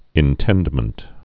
(ĭn-tĕndmənt)